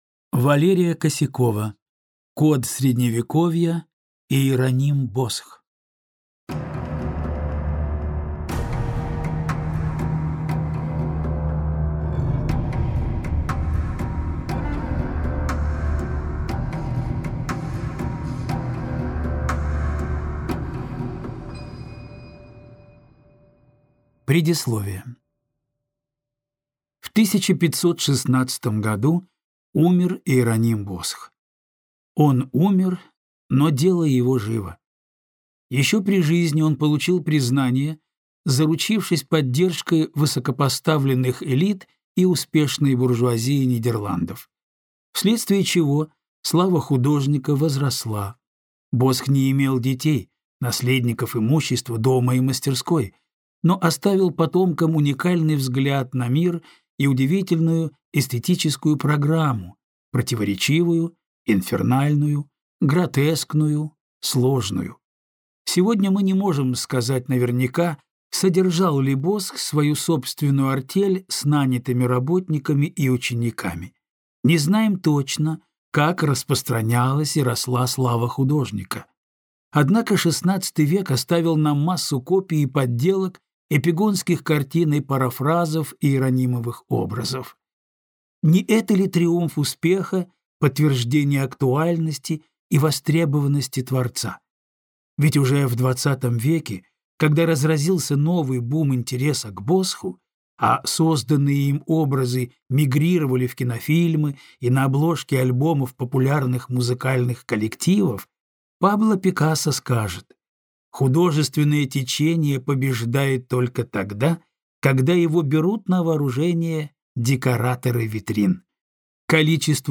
Аудиокнига Код средневековья. Иероним Босх | Библиотека аудиокниг